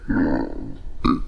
Titileo de Lampara
描述：免费声音，效果sonido artificial de lampara titilando